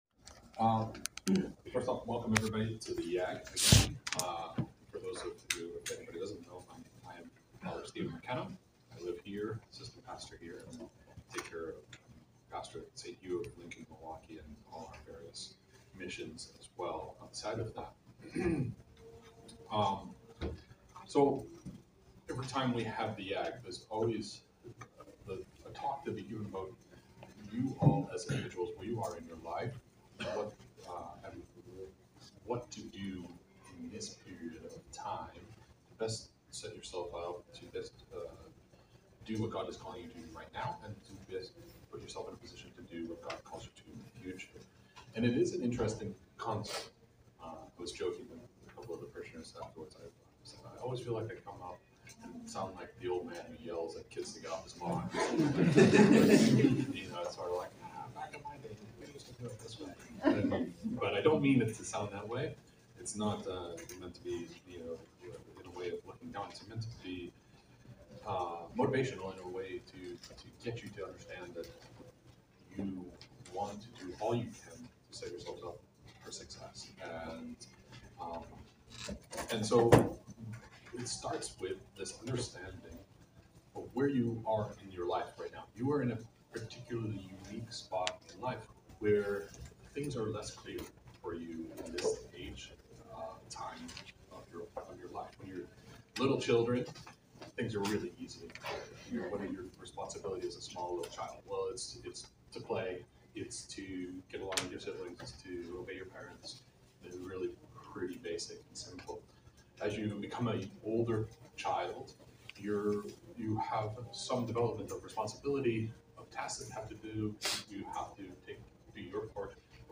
Saturday-YAG-speech-2025-mp3.mp3